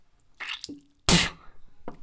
Escupitajo
Me gusta Descripción Grabación sonora del sonido producido por una chica al escupir (tirar un escupitajo).
Sonidos: Acciones humanas